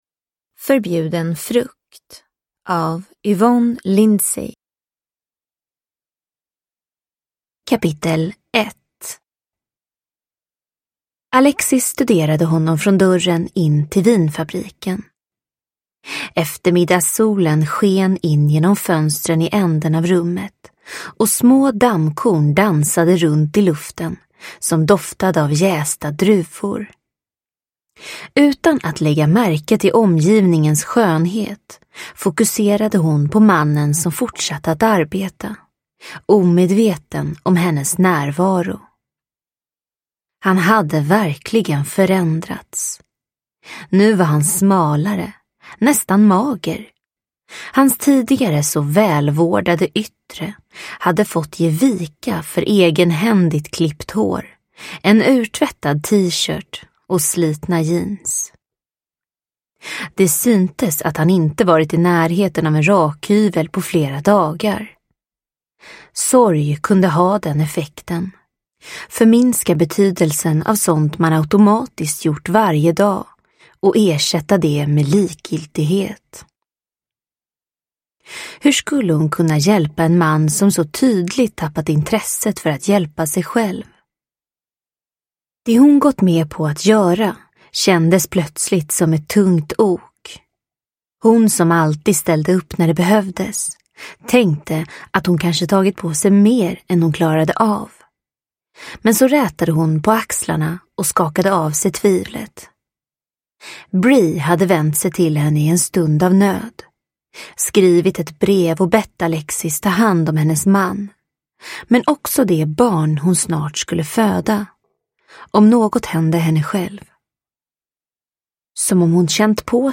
Förbjuden frukt – Ljudbok – Laddas ner